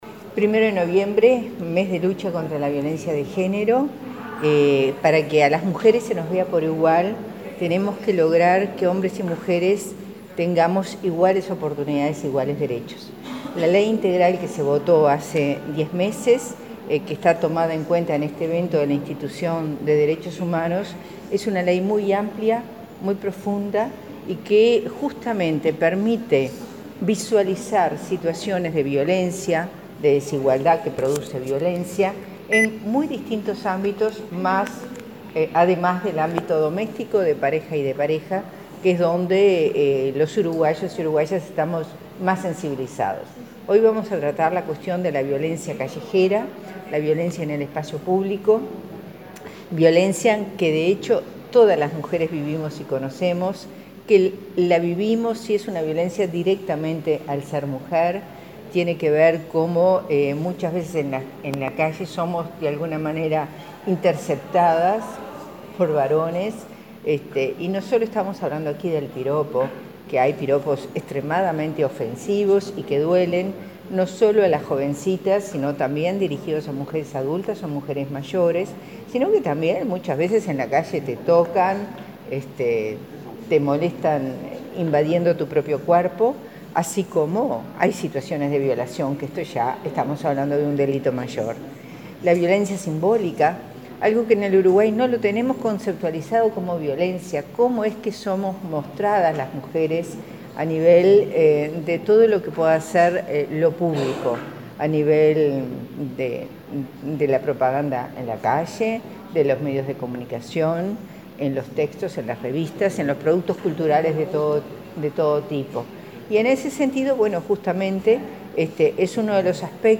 “Para que a las mujeres se nos vea por igual, tenemos que lograr que hombres y mujeres tengamos iguales oportunidades, iguales derechos”, dijo la directora del Instituto de las Mujeres, Mariella Mazzotti, en la apertura de la jornada de diálogo sobre el acoso callejero, en el marco del Mes de Lucha contra la Violencia de Género. Destacó que en Uruguay la ley 19580 revela acciones que generan violencia y promueve la reflexión.